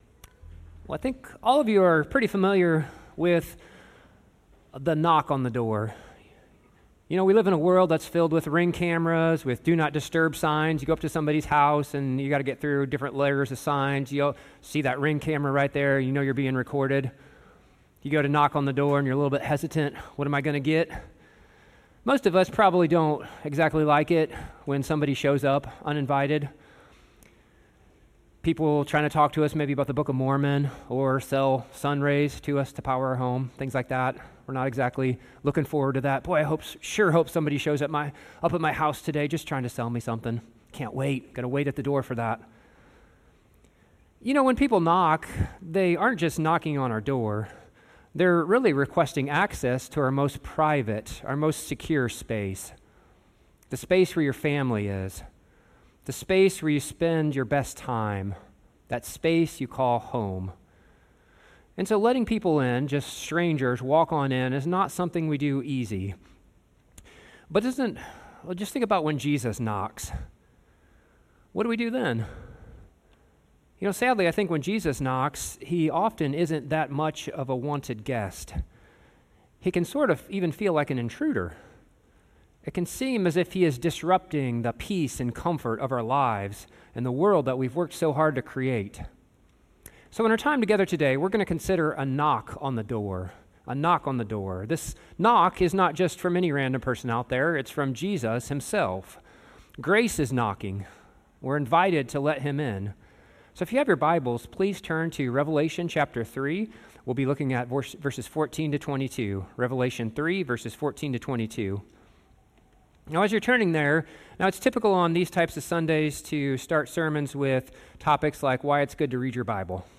Non-Series Sermon | New Castle Bible Church